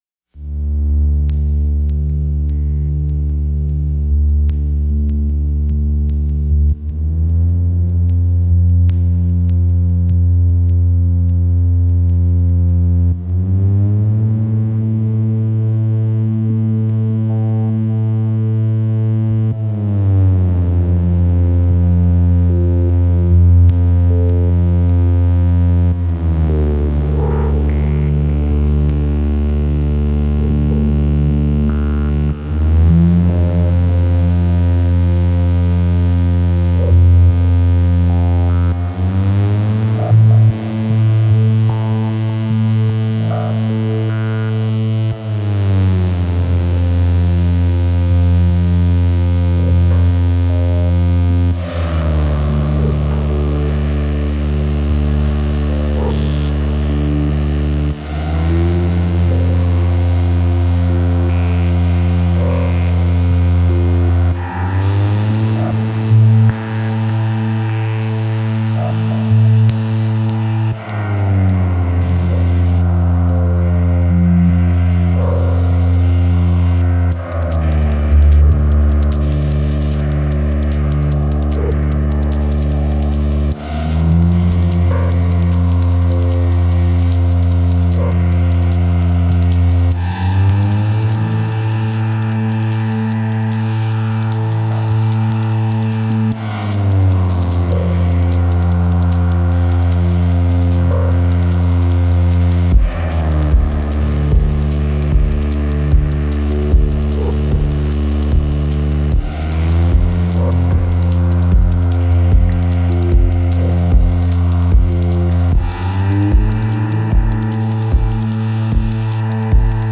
8 Swarm Machines